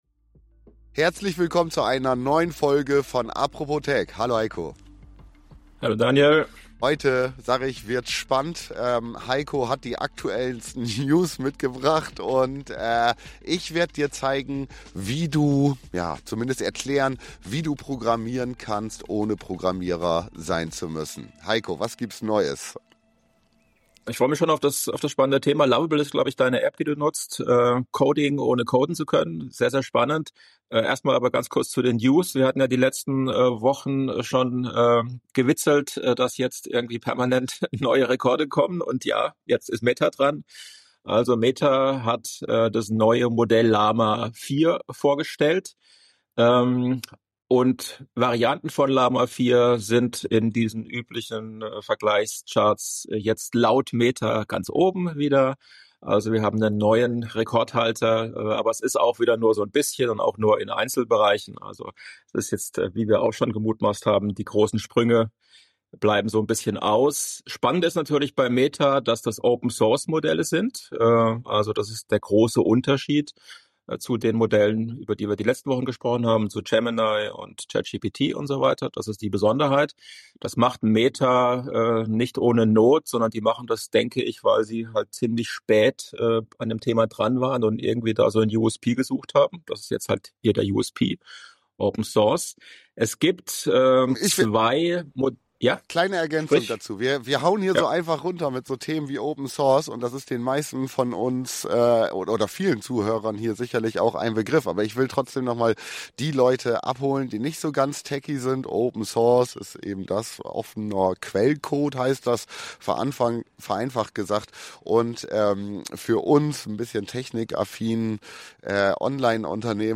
Ein Gespräch über Chancen, Trends und eine Zukunft, in der Tech plötzlich für alle zugänglich wird.